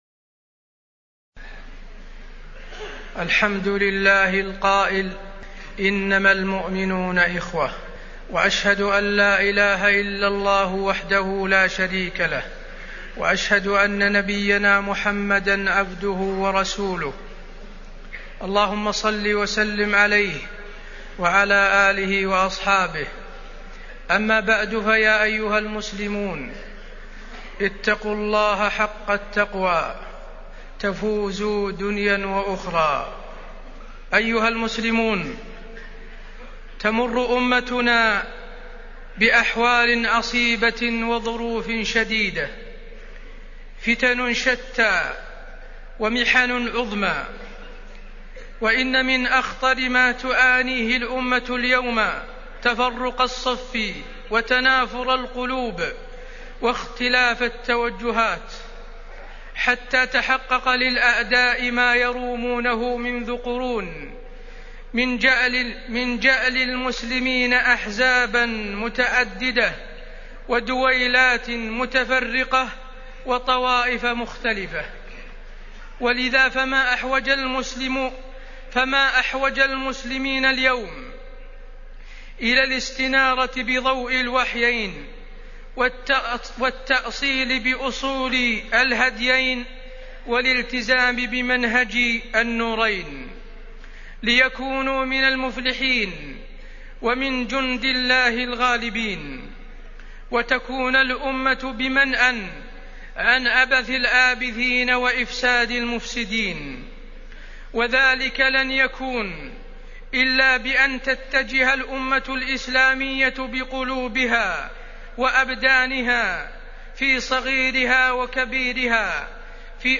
الدروس العلمية بالمسجد الحرام والمسجد النبوي
عنوان الخطبة التاريخ واقع الأمة الإسلامية